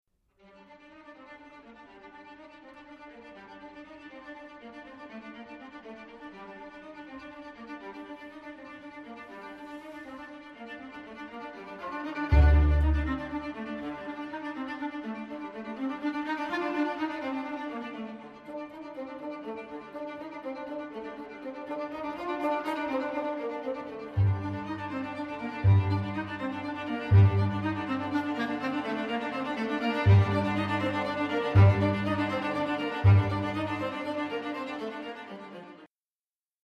Fassung für Kammerensemble
für Septett